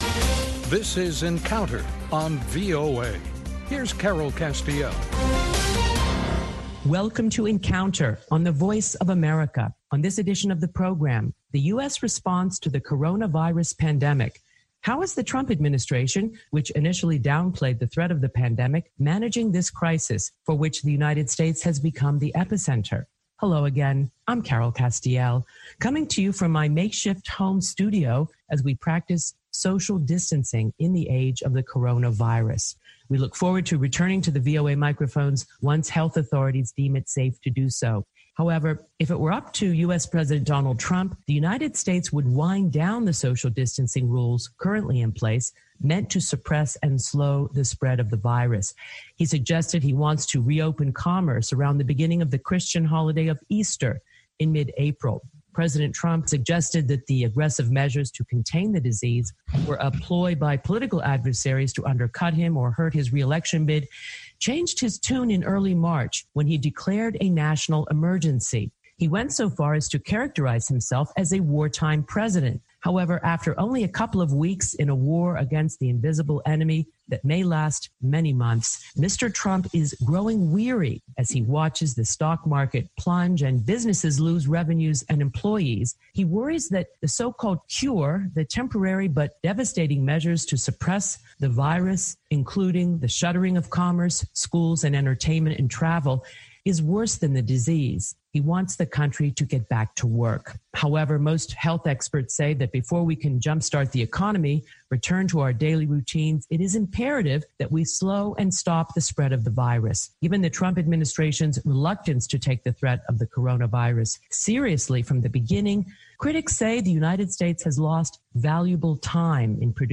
talk with host